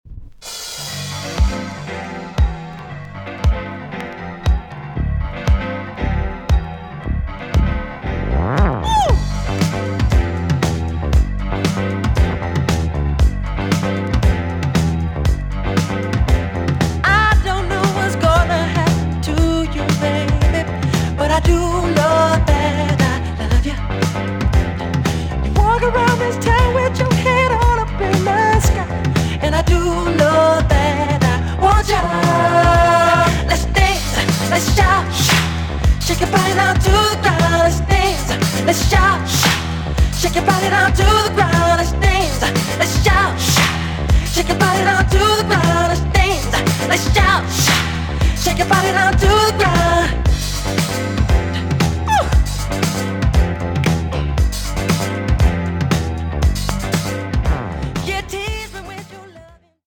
EX-音はキレイです。
1978 , NICE VOCAL TUNE!!